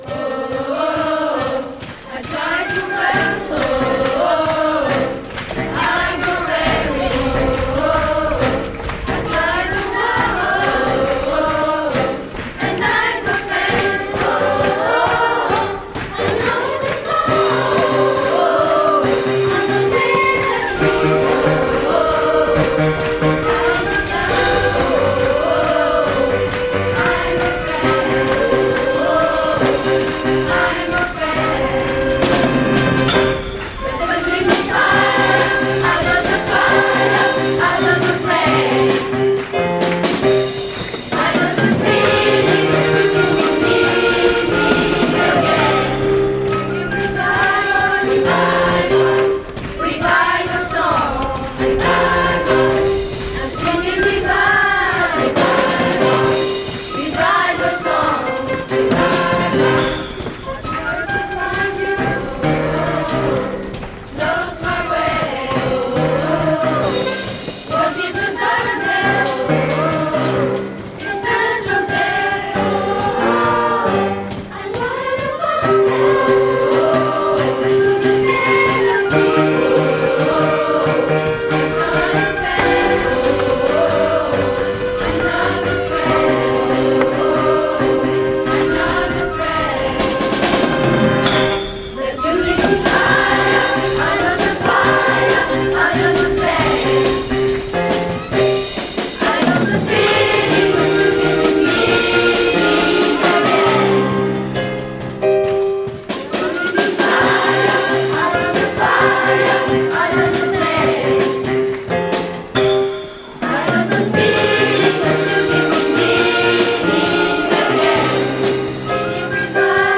Et pour prolonger le plaisir de chante ensemble, lors de la fête de la musique 2022, un concert privé en salle de musique a électrisé le public présent.